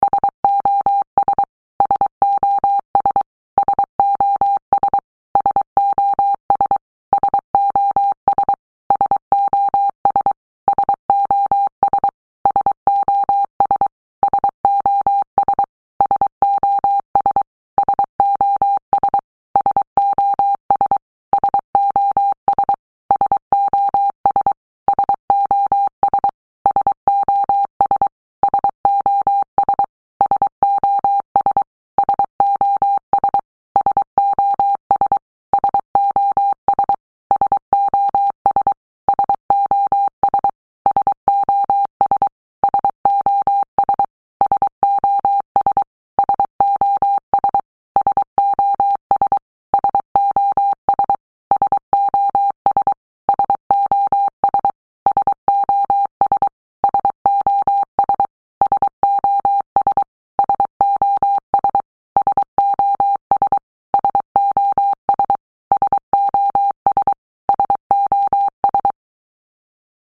SOS sound in Morse Code (Fast)